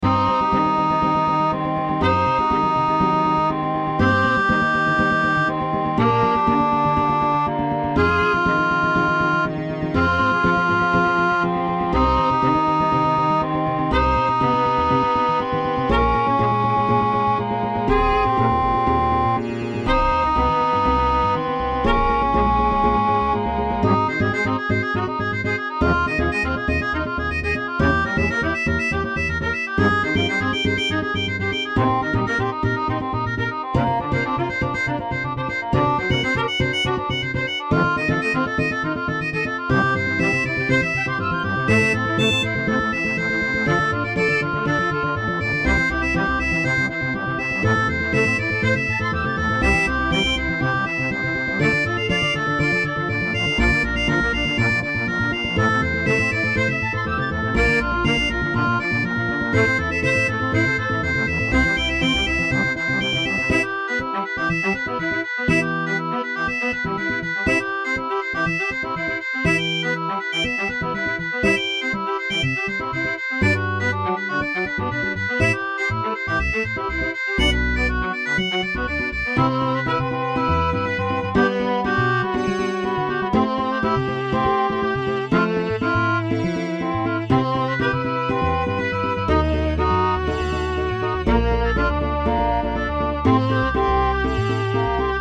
バグパイプ、フィドル、アコースティックベース、オーケストラル ハープ ※ケルト楽器
種類BGM